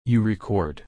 /ˈɹɛkɔːd/